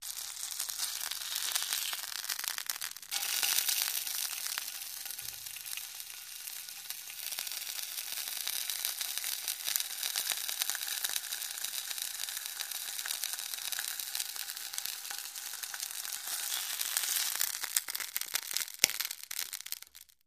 Cooking, Food Boils & Sizzles 3